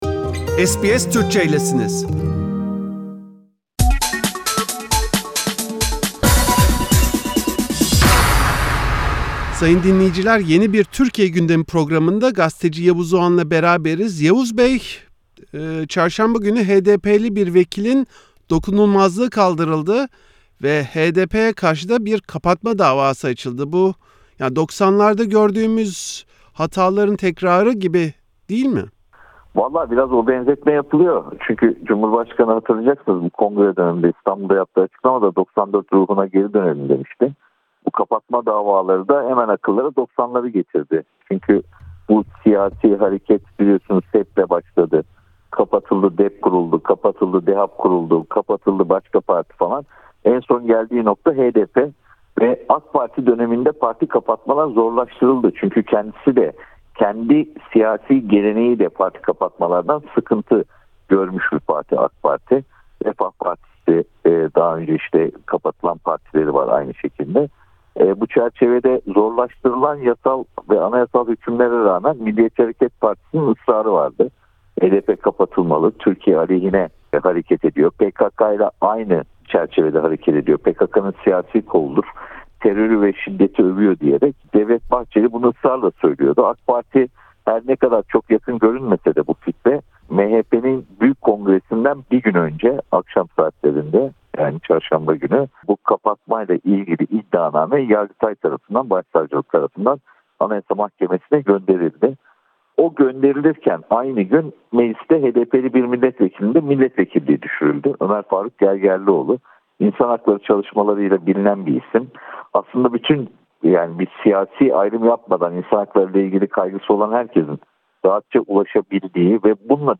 HDP’ye yönelip açılan parti kapatma davası, iktidardaki AK Parti’nin yıllardır karşı çıktığı prensipte geri adım anlamına geliyor. Gazeteci Yavuz Oğhan gelişmeleri SBS Türkçe için değerlendirdi.